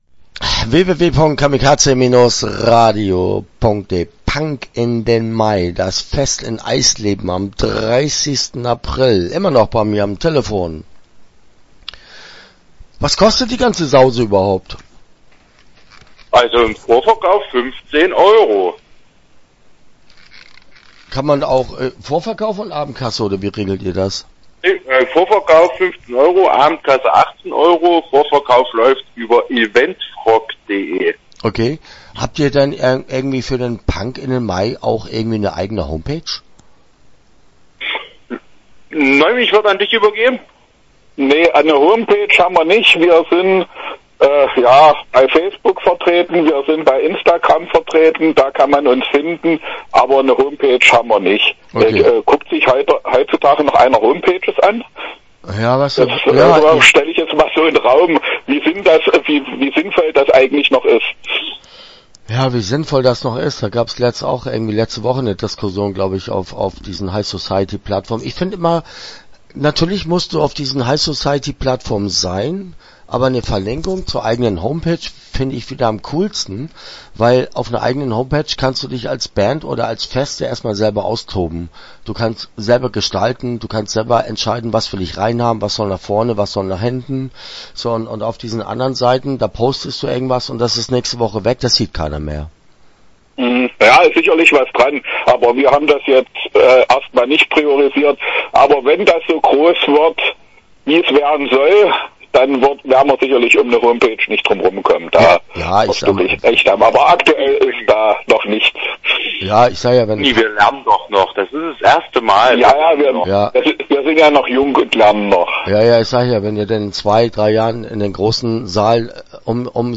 Start » Interviews » Punk in den Mai